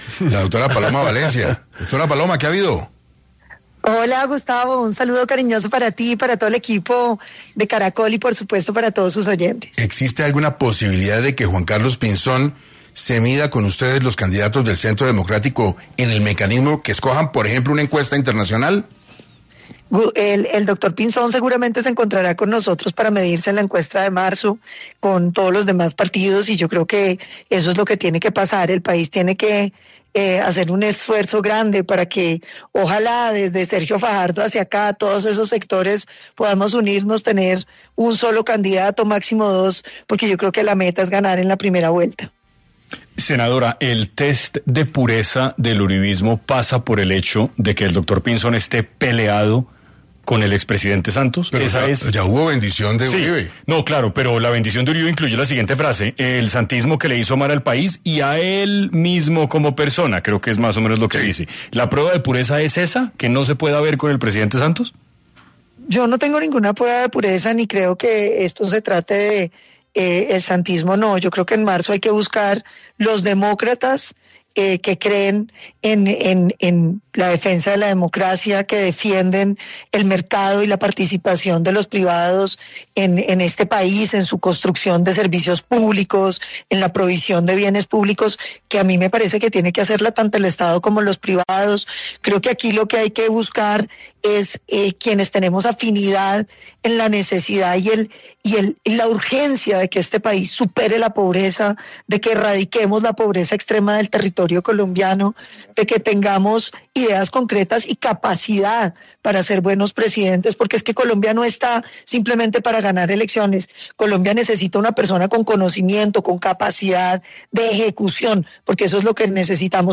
Frente a esto, la senadora de este partido político Paloma Valencia, habló este martes, 19 de agosto en el programa 6AM de Caracol Radio sobre lo que se viene para la coalición.